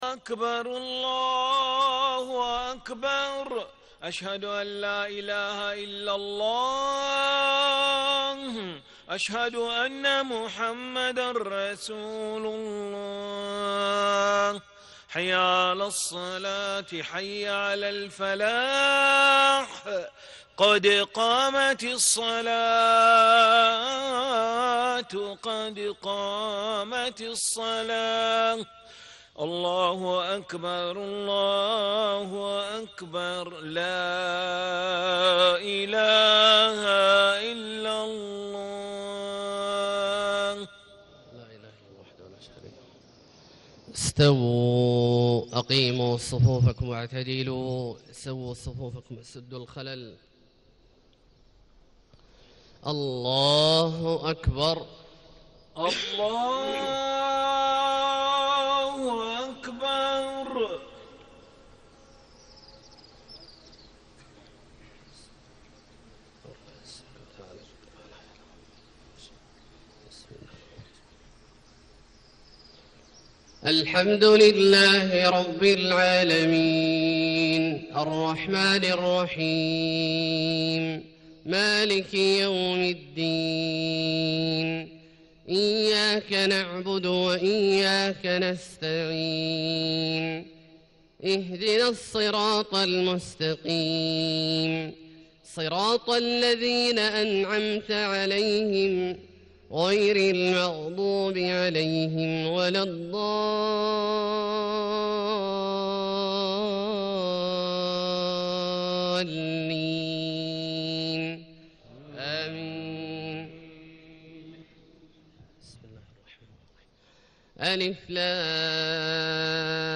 صلاة الفجر 1-7-1437هـ سورتي السجدة والإنسان > 1437 🕋 > الفروض - تلاوات الحرمين